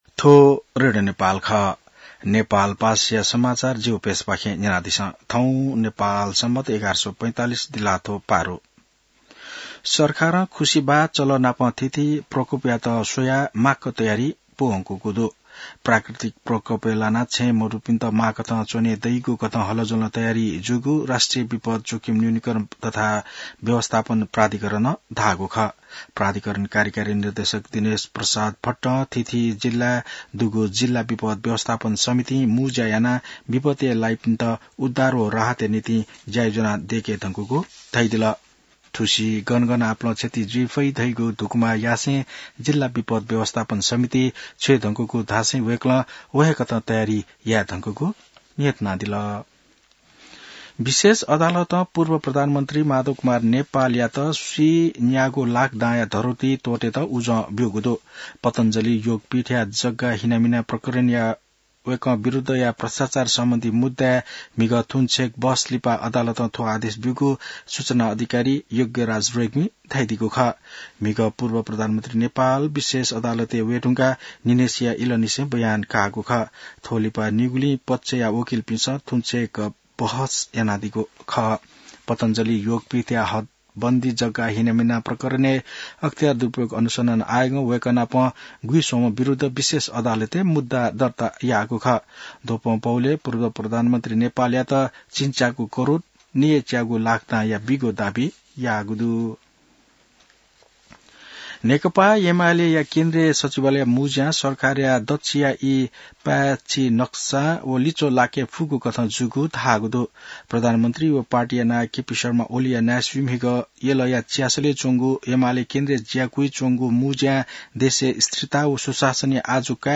नेपाल भाषामा समाचार : १२ असार , २०८२